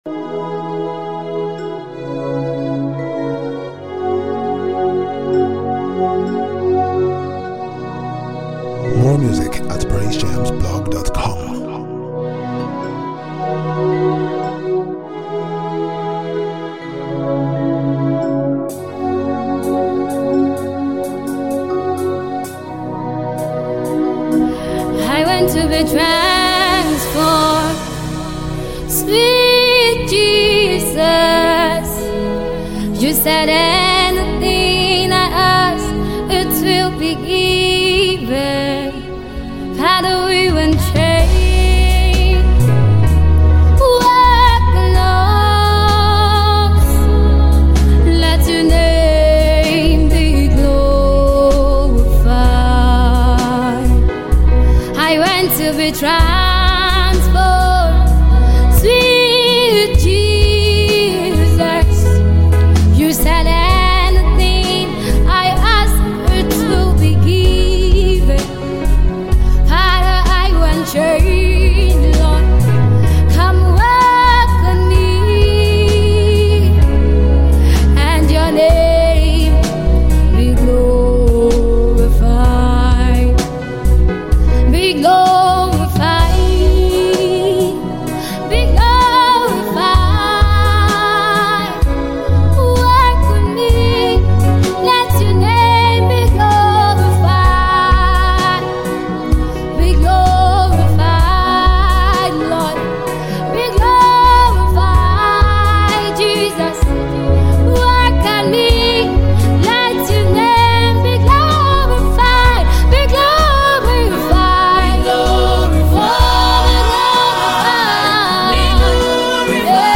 Nigerian dynamic Gospel Music group